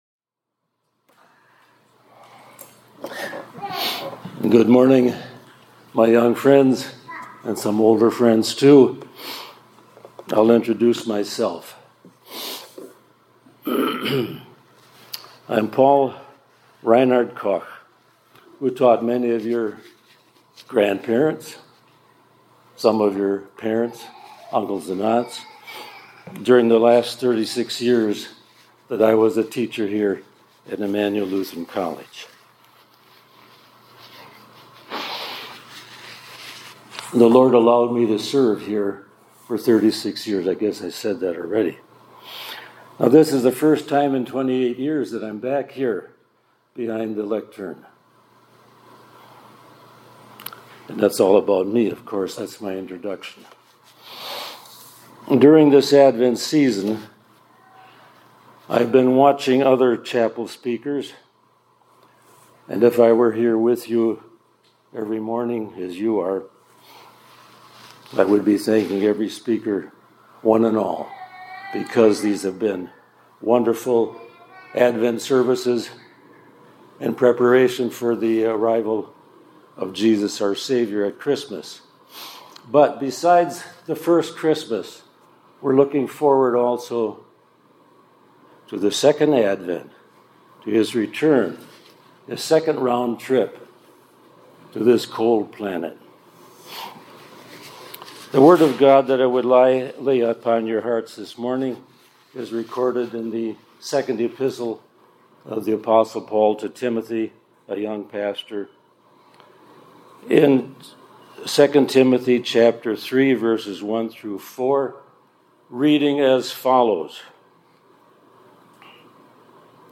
2024-12-12 ILC Chapel — Second Round Trip to a Cold Planet